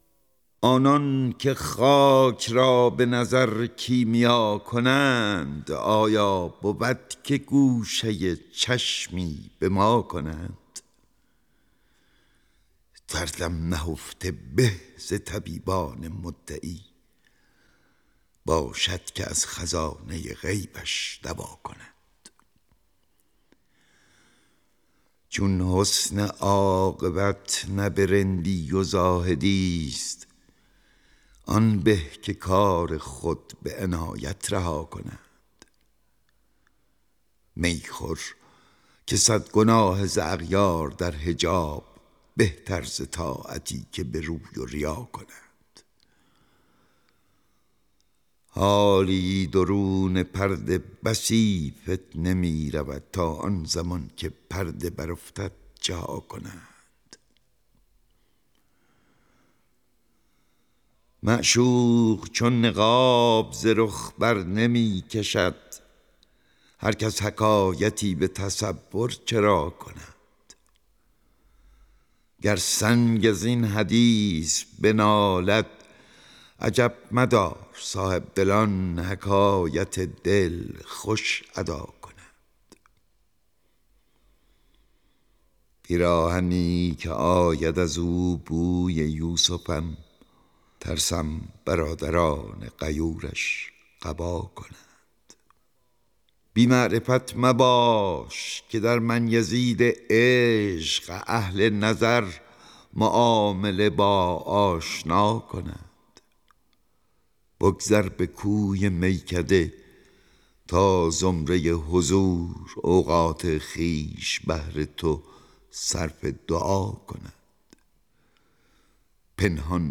دانلود دکلمه آنان که خاک را به نظر کیمیا کنند. با صدای احمد شاملو
گوینده :   [احمد شاملو]
آهنگساز :   فرهاد فخرالدینی